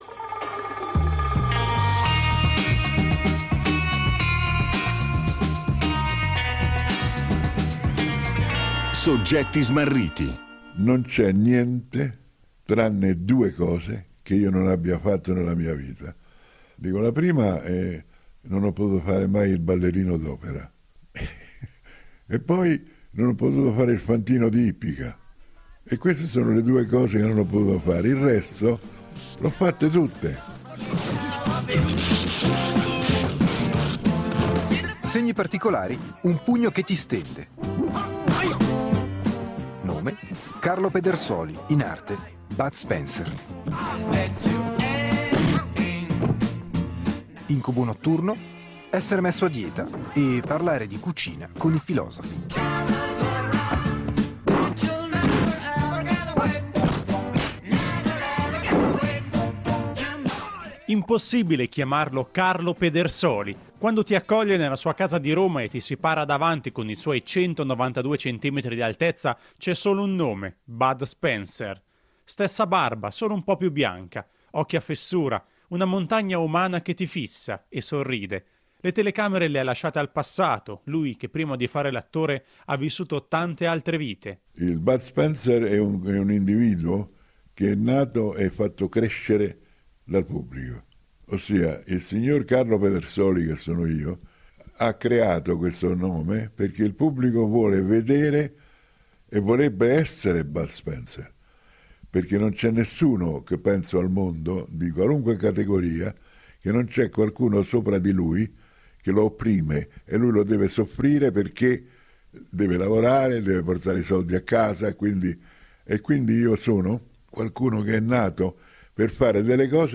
Carlo Pedersoli, alias Bud Spencer, racconta la sua vita tra sport, cinema e filosofia. Campione di nuoto, attore amatissimo, compagno inseparabile di Terence Hill, Bud svela aneddoti divertenti e riflessioni interessanti: dalla paura di essere messo a dieta alla pubblicazione del libro “Mangio ergo sum”.